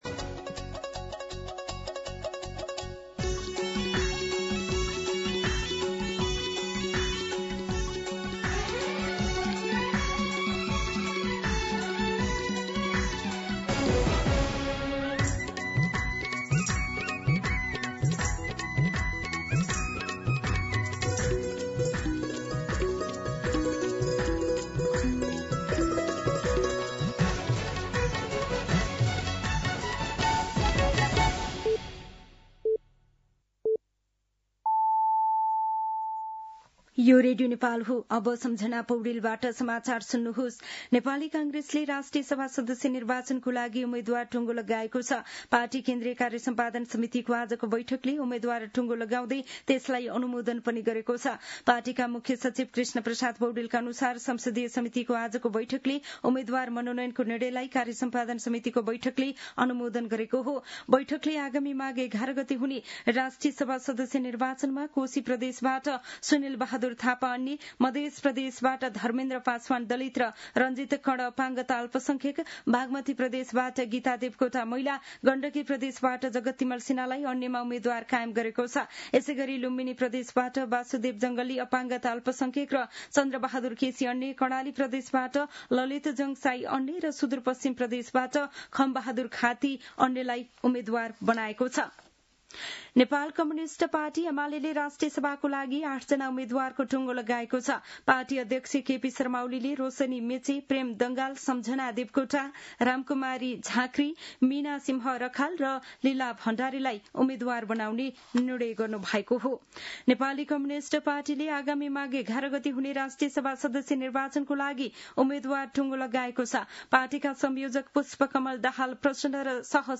दिउँसो ४ बजेको नेपाली समाचार : २३ पुष , २०८२
4-pm-Nepali-News-1.mp3